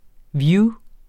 Udtale [ ˈvjuː ]